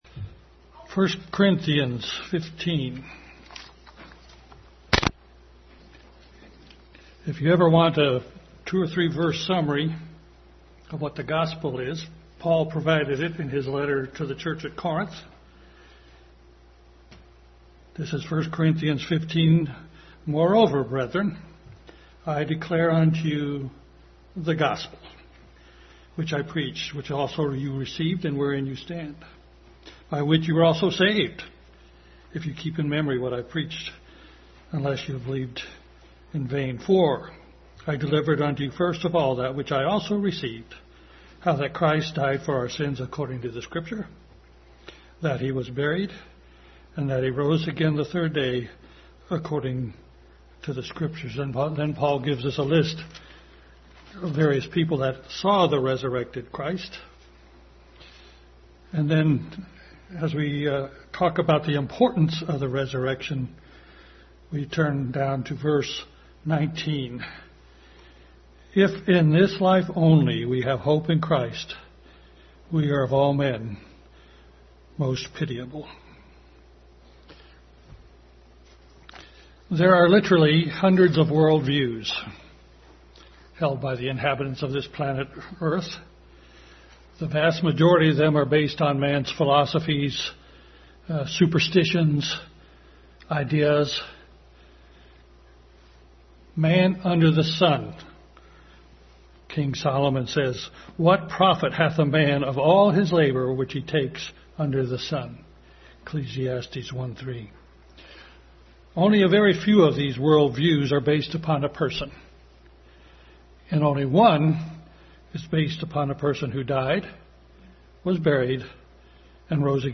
Easter 2023 Message.
Service Type: Family Bible Hour